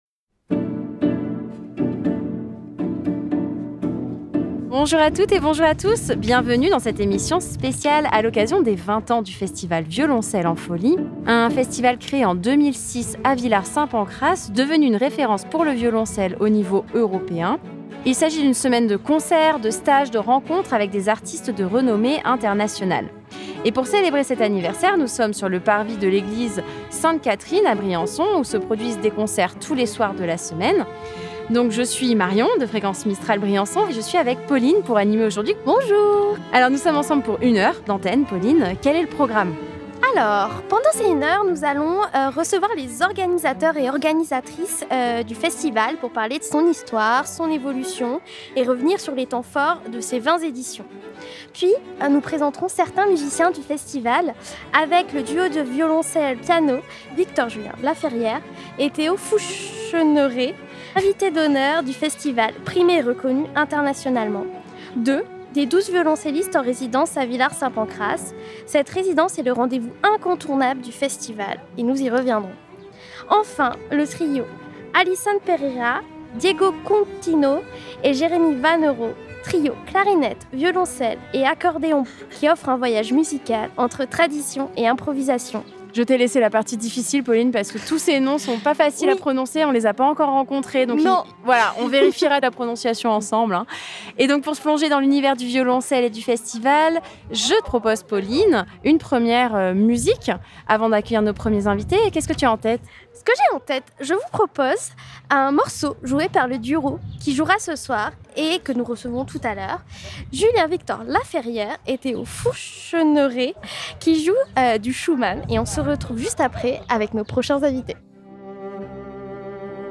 Fréquence Mistral s'est rendu sur place, pour une émission spéciale depuis le parvi de l'Eglise Sainte Catherine puis à Villard Saint Pancrace pour découvrir l'ambiance du village pendant la semaine de stage.